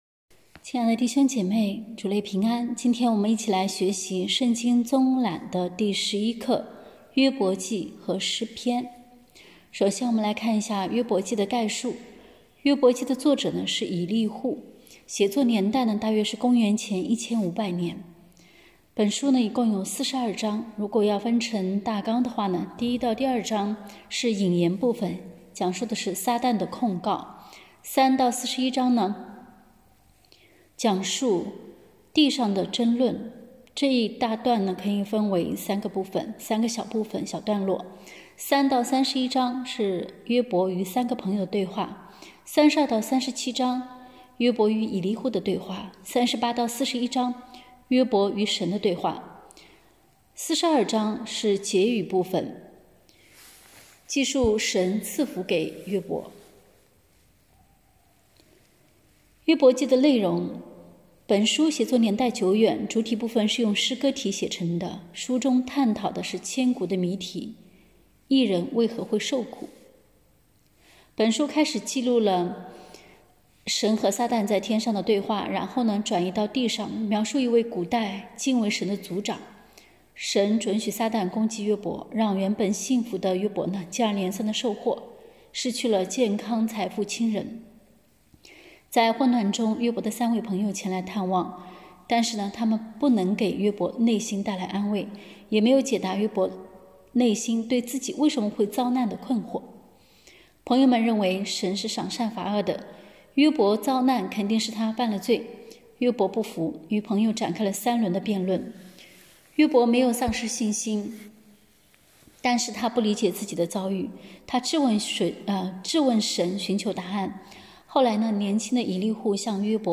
课程音频：